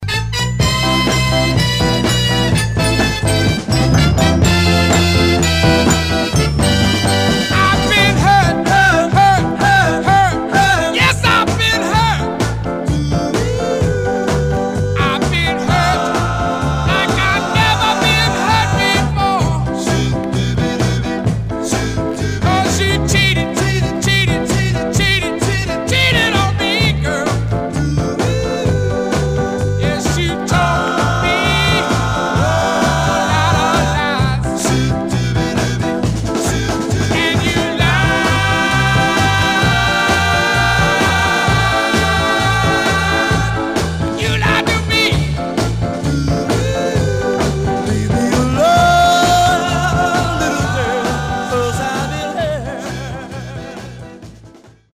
Mono
Soul